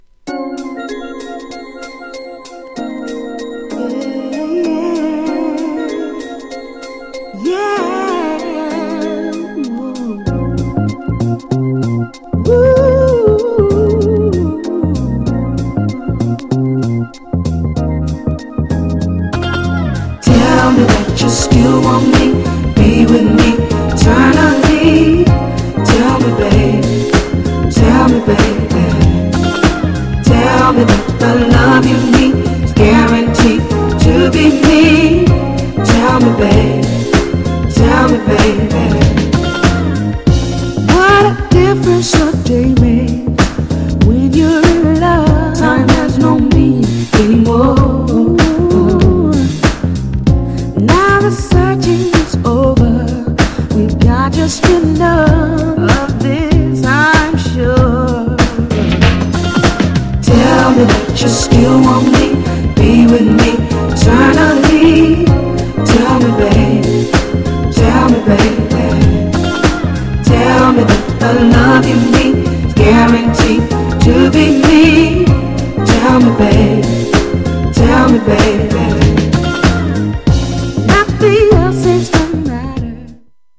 GENRE R&B
BPM 81〜85BPM
# JAZZY
# SAX # アーバン # クロスオーバー # ネオソウル # メロウ